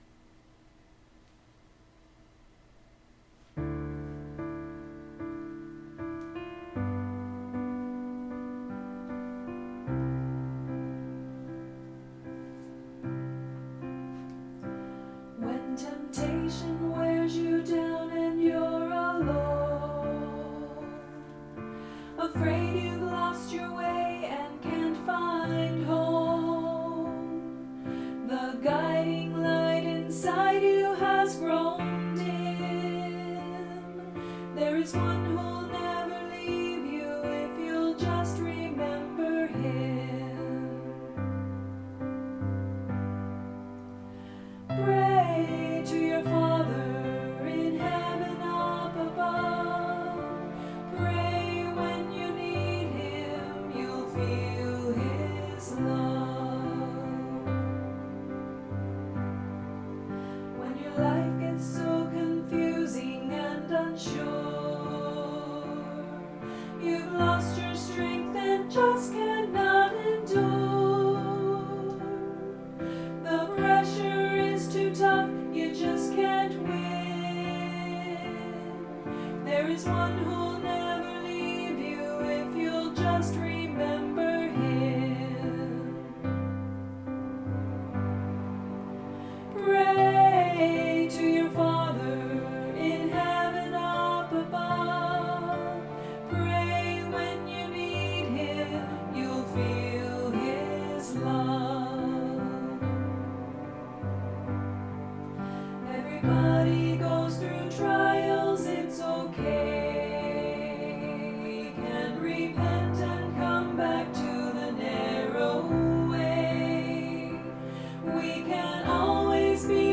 Duet
Voicing/Instrumentation: SA , Duet